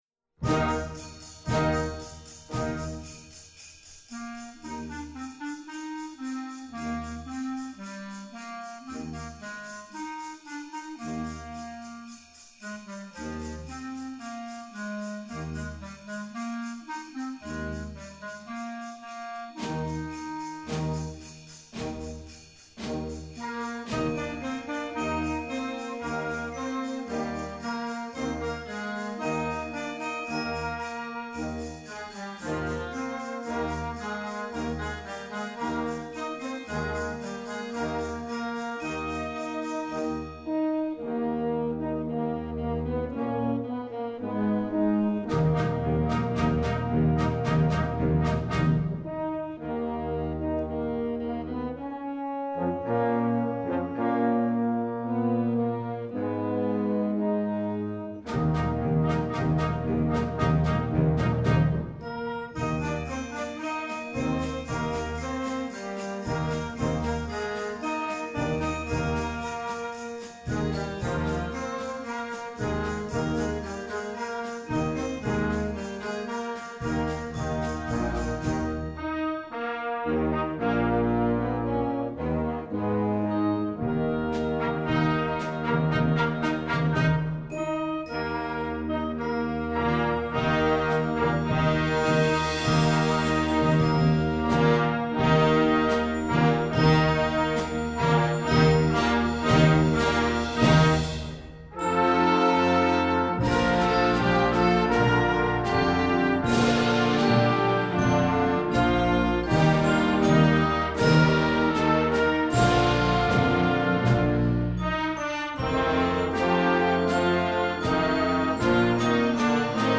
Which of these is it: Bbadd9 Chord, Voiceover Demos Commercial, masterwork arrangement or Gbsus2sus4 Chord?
masterwork arrangement